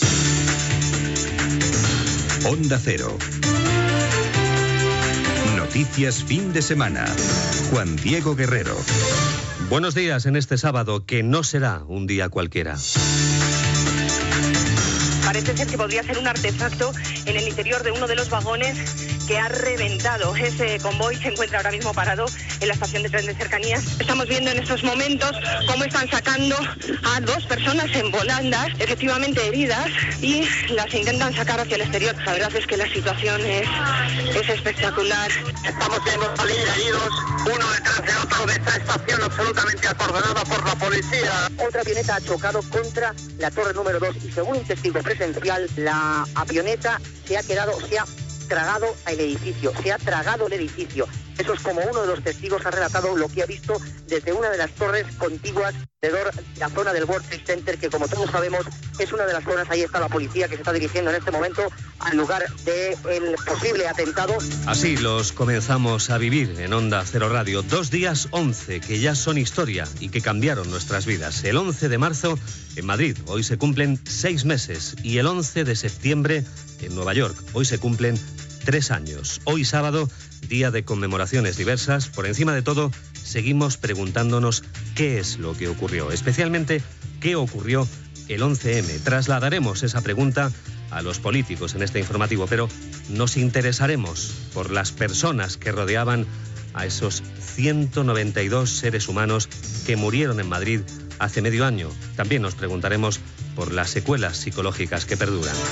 Careta del programa, talls de veu dels atemptats de l'11 de març de 2004 a diversos trens, a Madrid, i de l'11 de setembre de 2001 als Estats Units. El programa intentarà aprofundir en el que va passar.
Informatiu